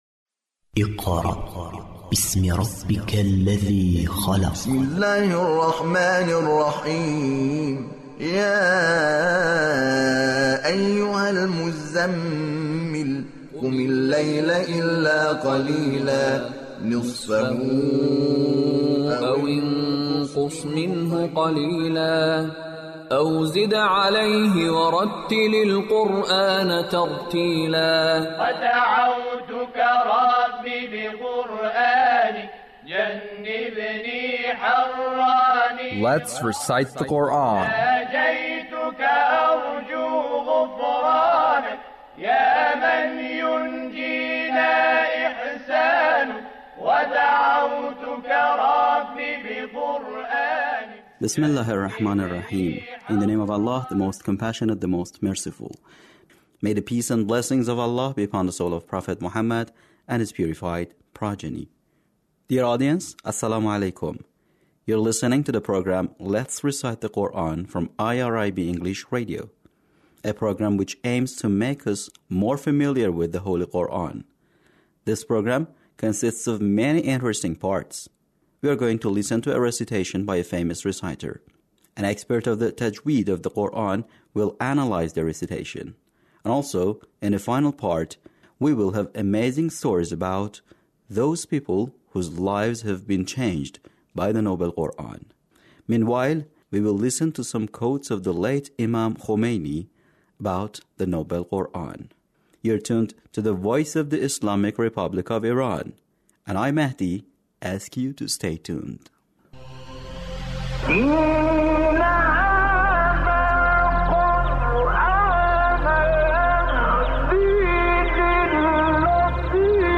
Recitation of surah al-Tahrim - Attractiveness of the Noble Quran